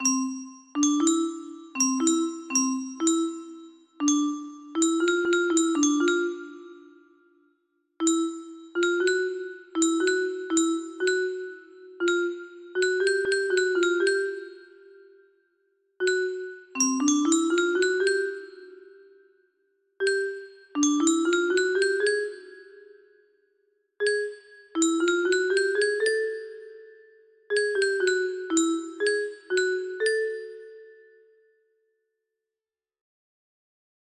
Unknown Artist - Untitled music box melody
Grand Illusions 30 music boxes More